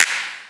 edm-clap-41.wav